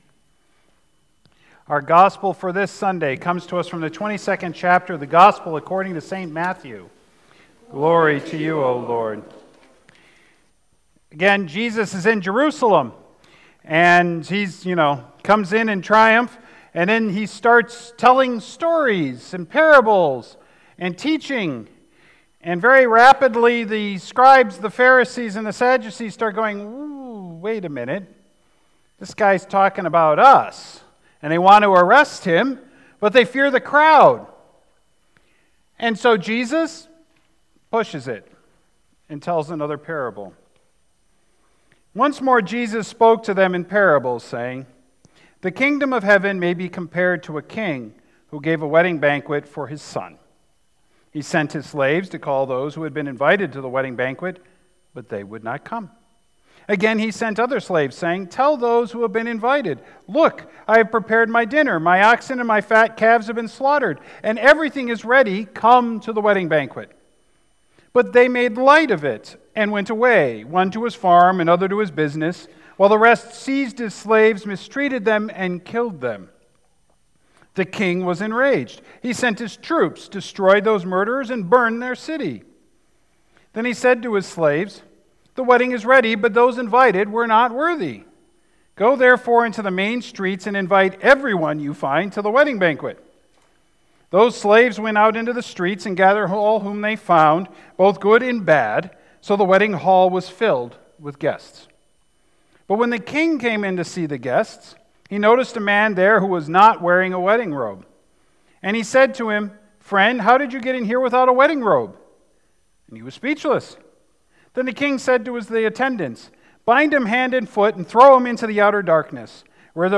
Sermons | Beautiful Savior Lutheran Church